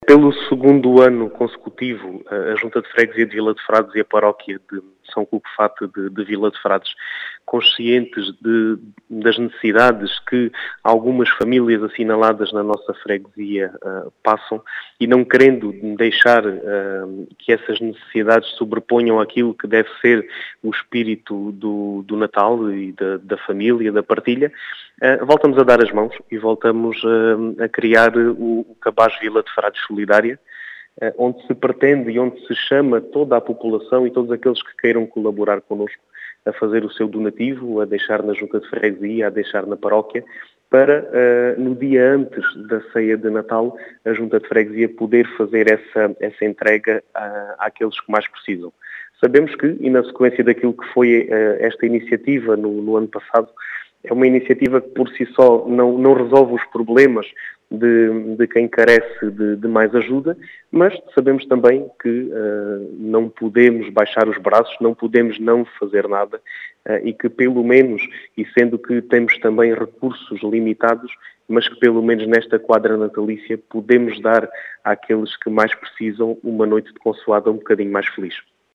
As explicações são de Diogo Conqueiro, presidente da junta de freguesia de Vila de Frades, que quer “dar aos que mais precisam” um Natal mais feliz.